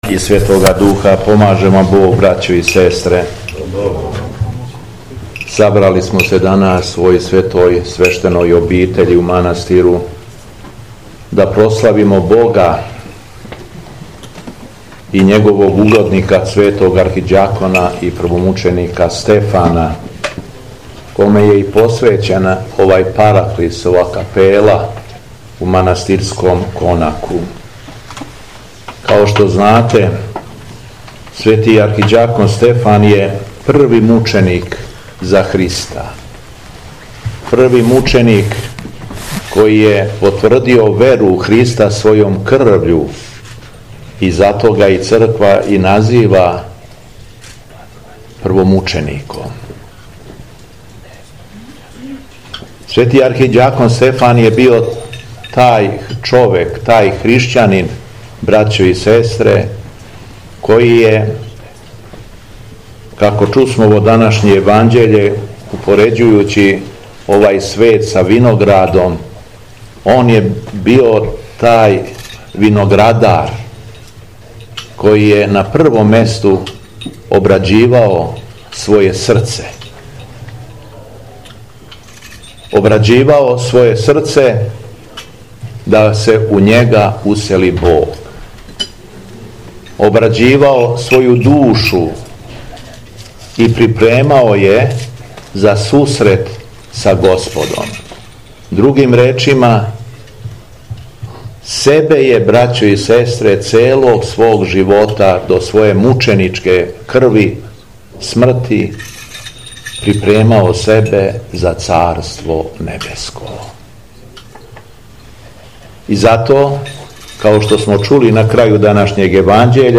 На обронцима планине Рудник, у манастиру Никоље, а поводом славе манастирске капеле, Преноса моштију Светог Архиђакона и Првомученика Стефана, 15. августа, 2024. године, Његово Високопреосвештенство Митрополит шумадијски Господин Јован служио је Свету Архијерејску Литургију.
Беседа Његовог Високопреосвештенства Митрополита шумадијског г. Јована
На проповеди сабраном народу владика Јован је рекао: